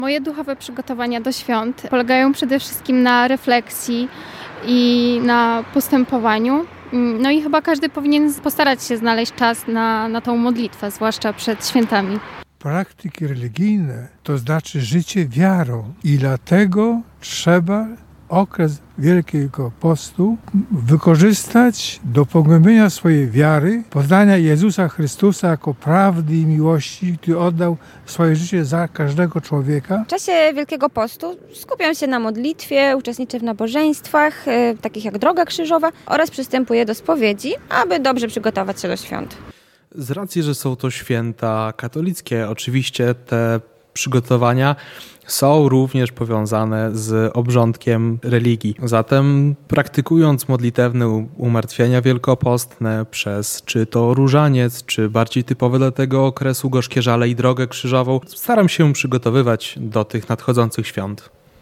Pytaliśmy mieszkańców Łomży, o tym, czy Wielkanoc ma dla nich wymiar duchowy. Jak się okazuje, nasi rozmówcy bardzo poważnie traktują religijny wymiar Świąt.
Aktualności | Pilne 3 | Sonda | Wiara